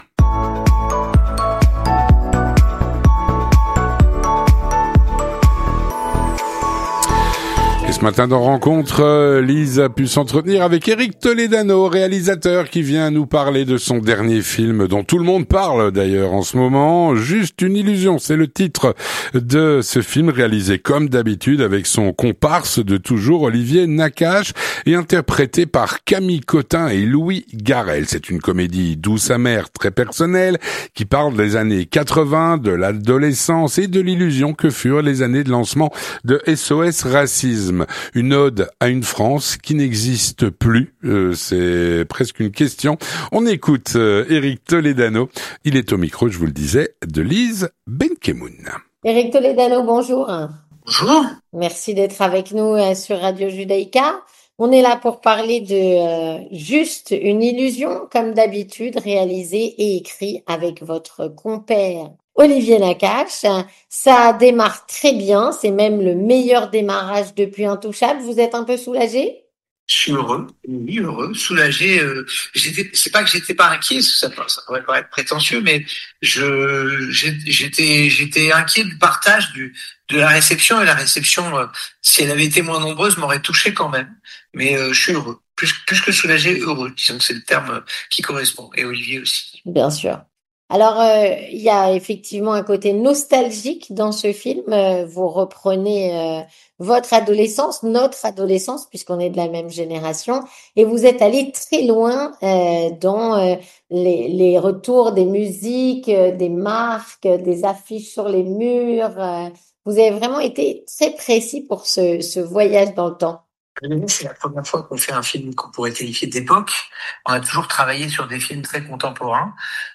Éric Toledano, réalisateur, vient nous parler de son dernier film, “Juste une illusion”, réalisé comme d’habitude avec son complice de toujours, Olivier Nakache, et interprété par Camille Cottin et Louis Garrel.